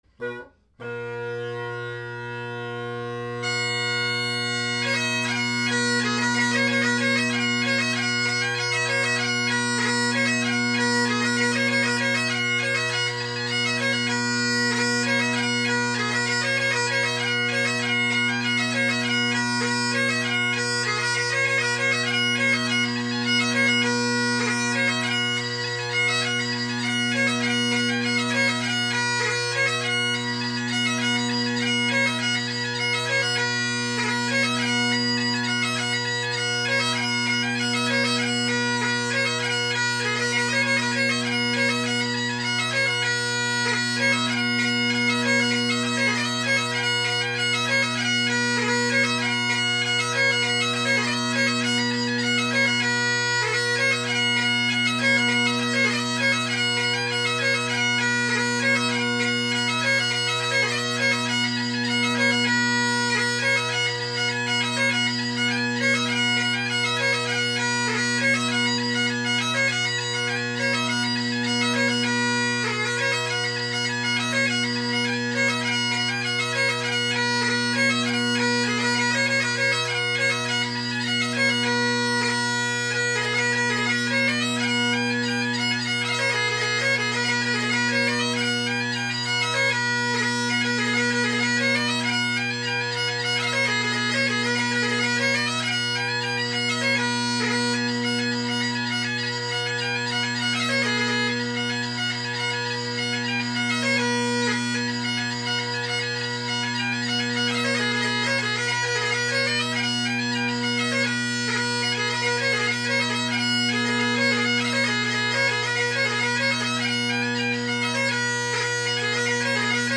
Great Highland Bagpipe Solo
It’s a very bass dominant sound at the moment.
Donald MacLeod and Rakes of Kildare – facing away from mic